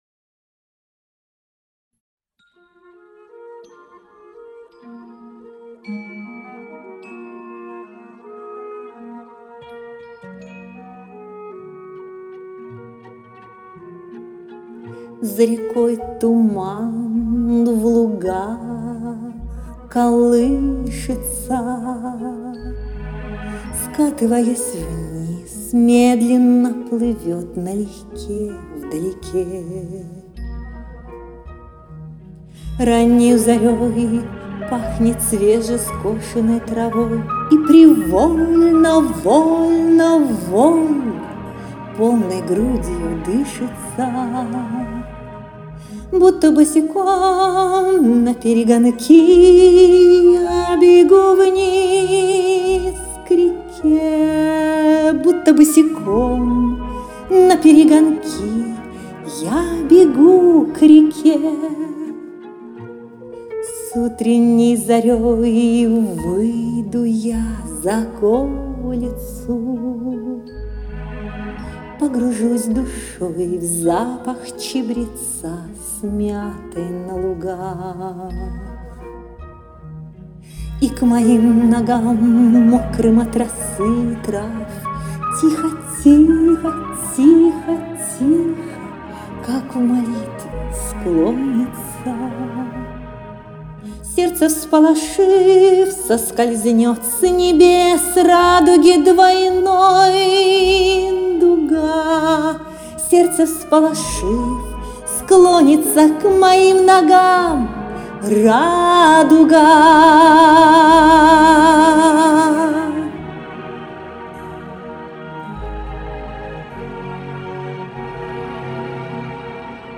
На мелодию вальса